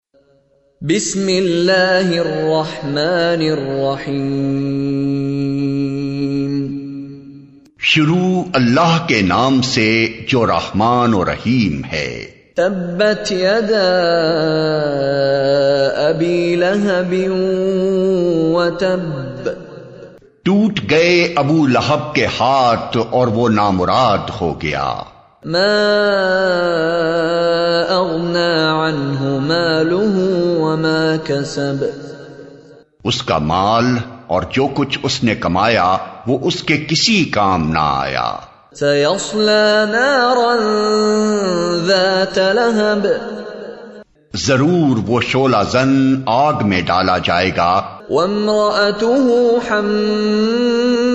He is the Imam of the Grand Mosque of Kuwait where he leads the Taraweeh prayers every Ramadan.
Surah Al Lahab with Urdu Translation by Sheikh Mishary Rashid.MP3